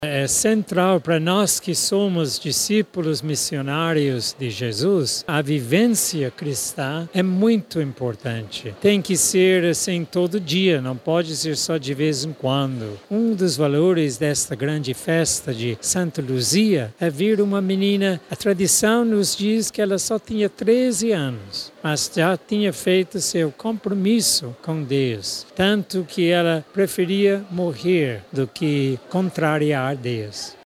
A Santa Missa teve início às 16h e foi presidida por Dom Derek John Byrne, bispo emérito da Diocese de Primavera do Leste, no Mato Grosso, que atualmente reside em Manaus. Durante a homilia, Dom Derek destacou a importância da vivência constante da fé e da esperança na caminhada cristã.